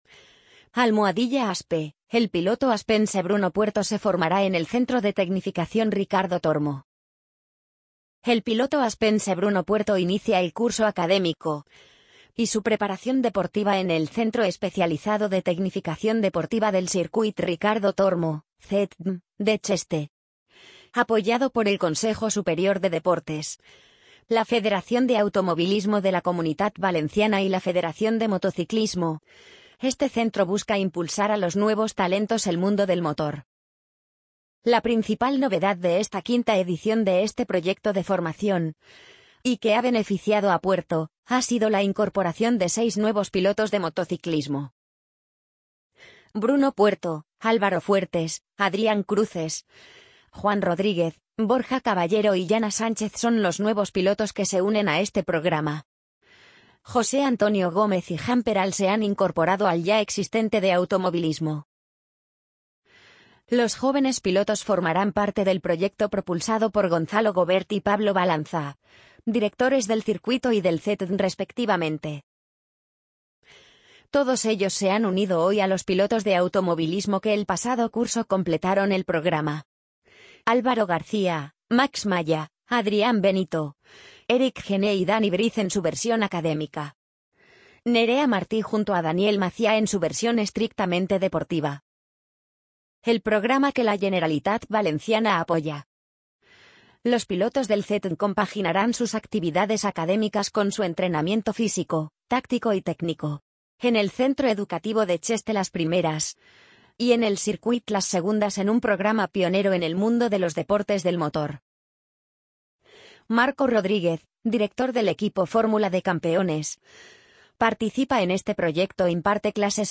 amazon_polly_60000.mp3